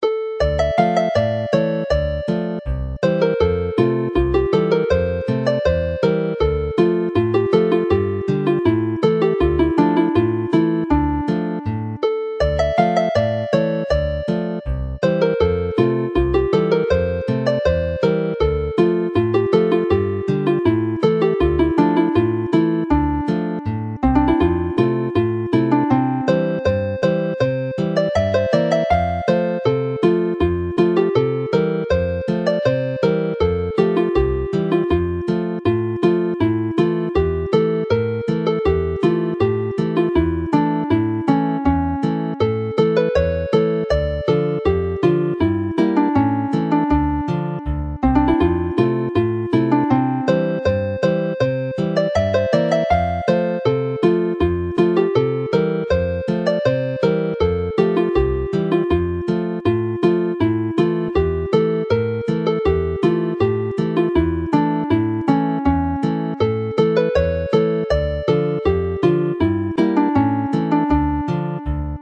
Alawon Cymreig - Set Sawdl y Fuwch - Welsh folk tunes to play -
Play the tune slowly